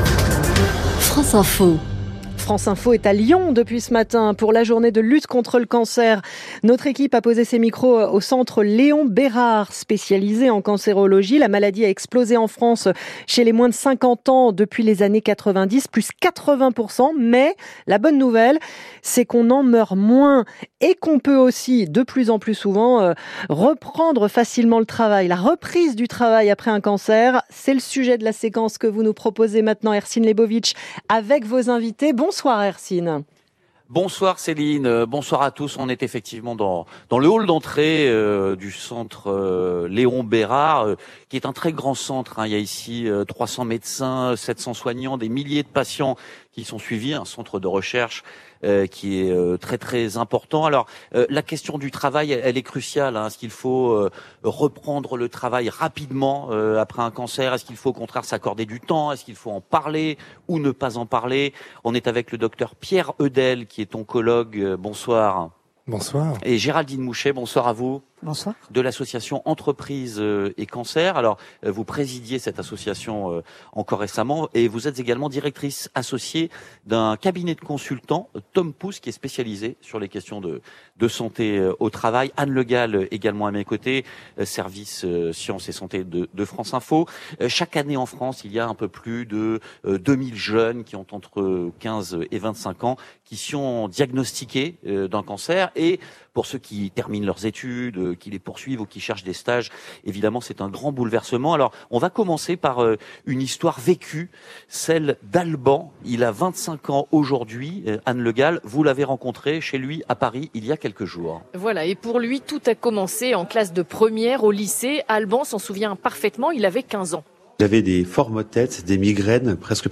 À l’occasion de la Journée mondiale contre le cancer, nous avons eu l’honneur de participer à une émission de radio consacrée à un sujet central pour les entreprises et les salariés : le retour au travail après un cancer.